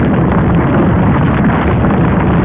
mega_roll.wav